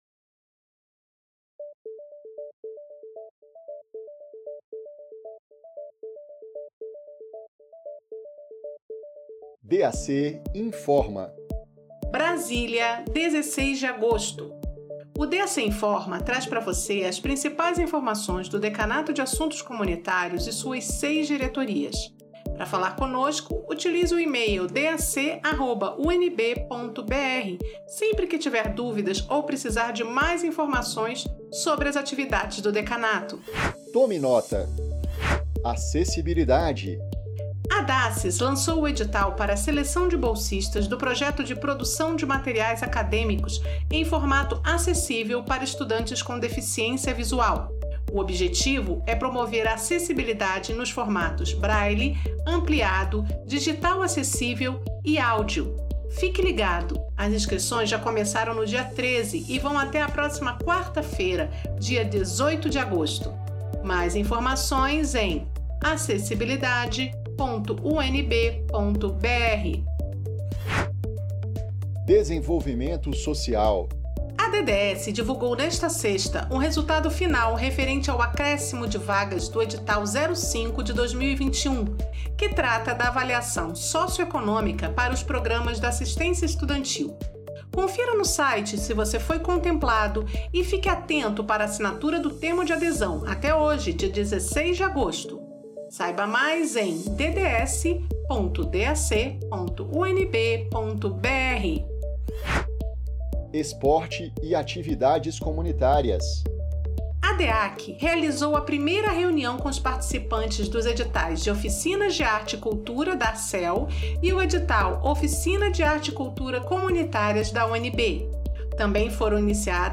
O boletim DAC Informa traz semanalmente as informações mais relevantes do Decanato de Assuntos Comunitários e de suas seis diretorias.
Além da página de internet do decanato, as versões escrita e em áudio podem ser acessadas em aplicativos de comunicação e por e-mail via InfoUnB.